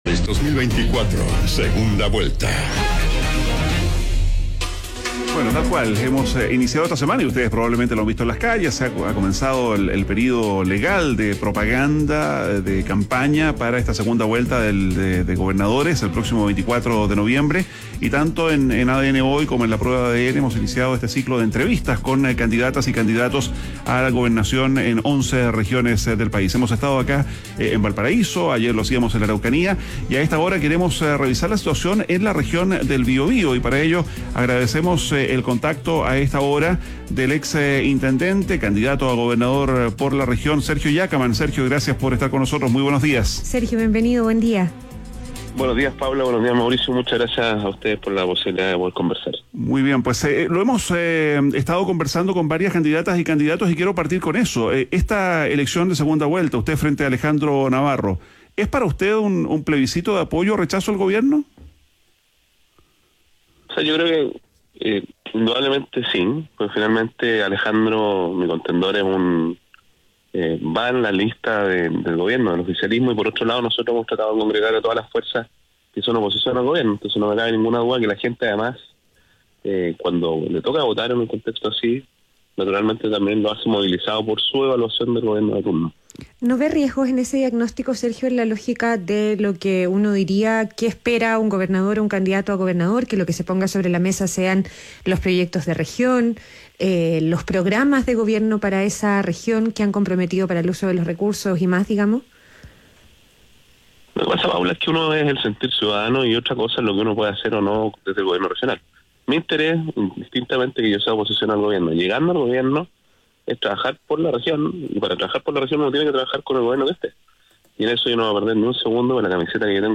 En conversación con ADN Hoy, el candidato a gobernador por la región detalló los desafíos que presenta la región, destacando la seguridad.